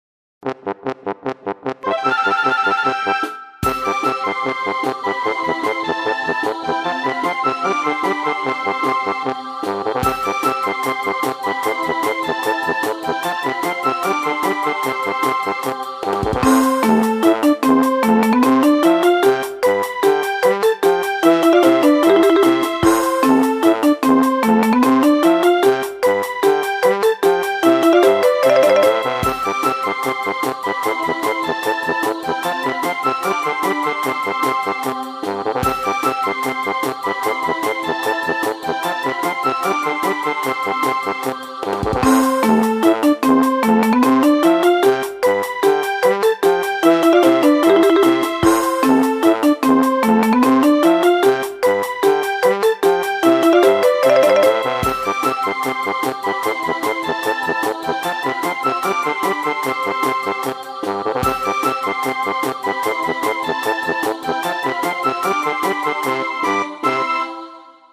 - East West Symphonic Orchestra
Composé pour un jeu de plate-formes, ou un univers très enfantin/comique.
Et les "tidoudidou" effet xylophone me rappellent la douce époque des Mario!